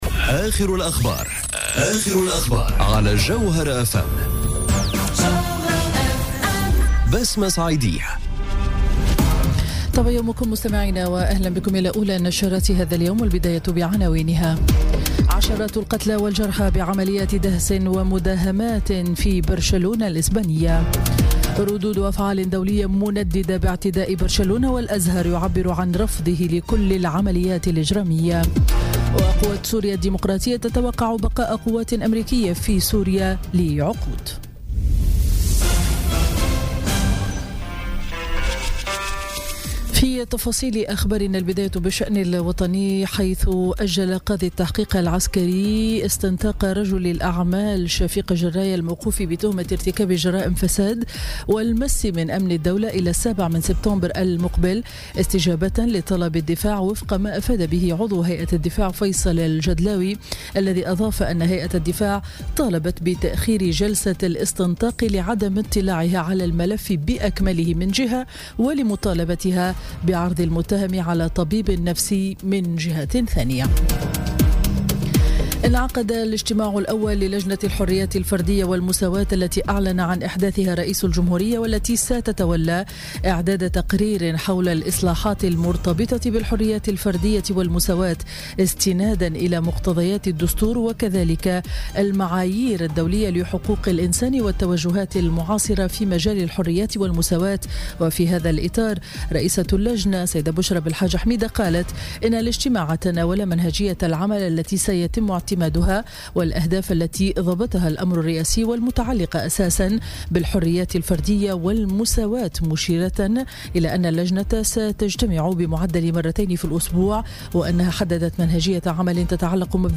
نشرة أخبار السابعة صباحا ليوم الجمعة 18 أوت 2017